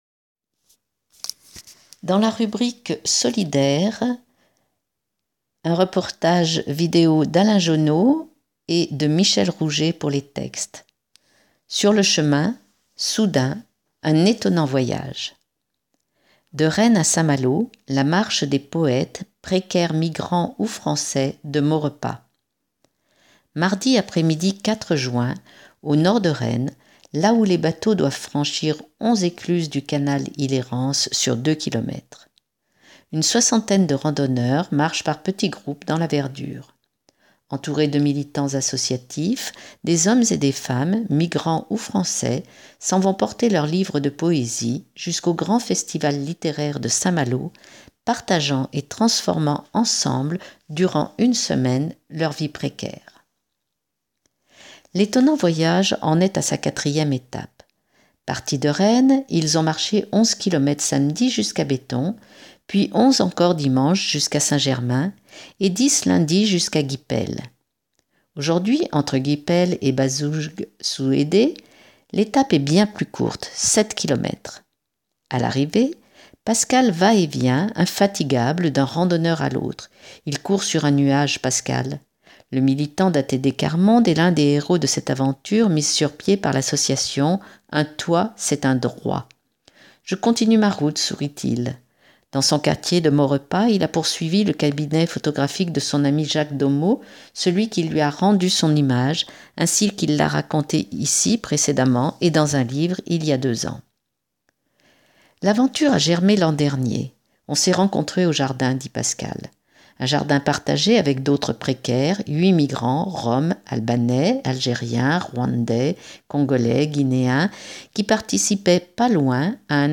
Une soixantaine de randonneurs marchent par petits groupes dans la verdure.